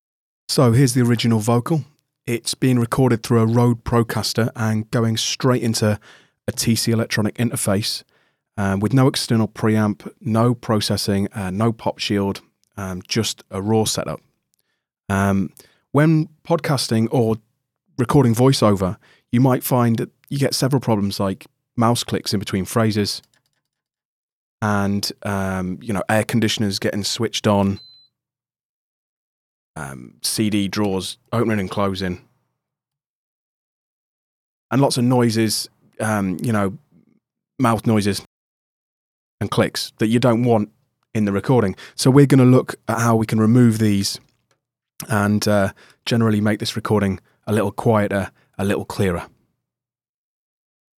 I’ve used around 3-4 dB of gain reduction, combined with slow attack and release times.
Compressed and de-essed!